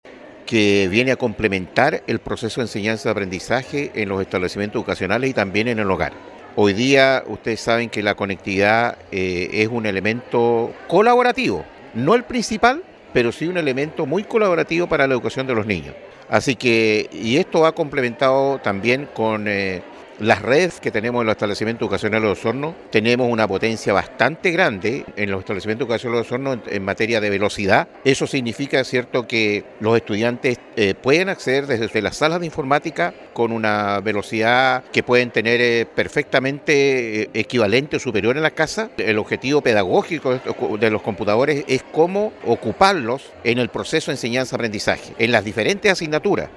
En el gimnasio de la Escuela Monseñor Francisco Valdés Subercaseaux, de Osorno, y por medio de una ceremonia simbólica junto a sus alumnos y los de Escuela Juan Ricardo Sánchez, se dio el vamos a la entrega de los equipos computacionales suministrados por el “Programa de Becas de Tecnología de la Información y Comunicación” de la Junta Nacional de Auxilio Escolar y Becas (JUNAEB) y que este 2023, tiene por beneficiarios a 885 estudiantes de 7mo básico de recintos formativos municipales de la comuna.